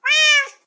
meow4.ogg